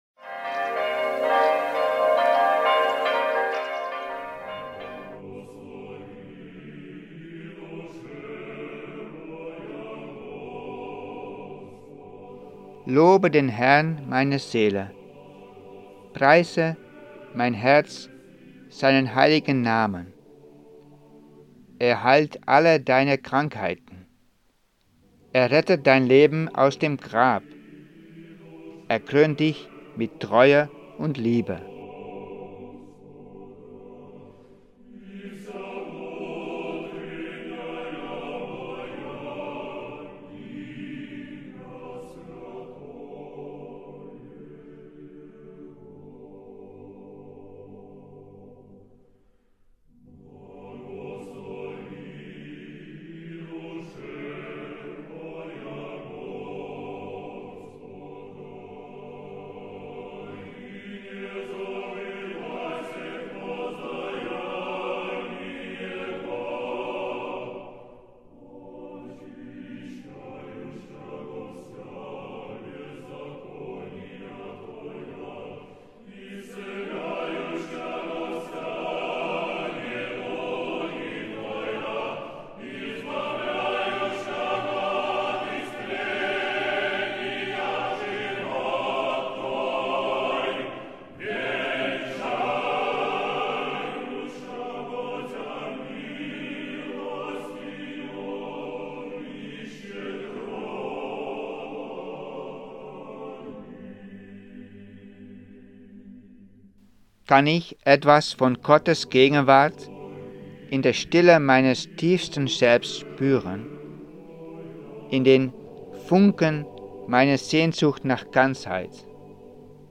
Meditation Montag der Karwoche